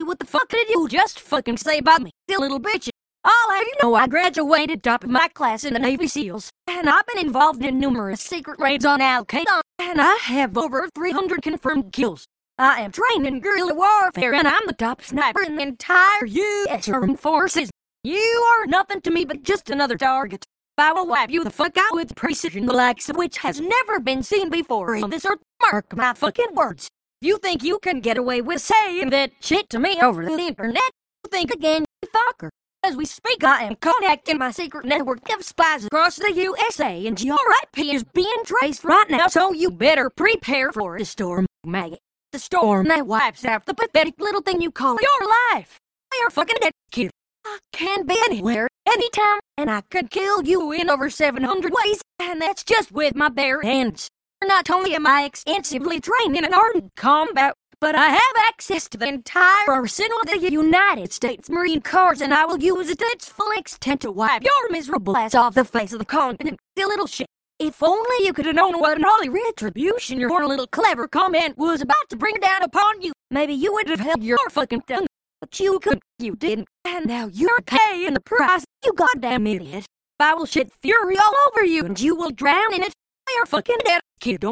This exists! It's called "unit selection", and was an early method for generating high quality speech before everything became statistical.
But I still like this technique because it feels like a YTPMV shitpost (same technique, after all), but also has moments where it sounds more natural than any TTS because it uses the real samples.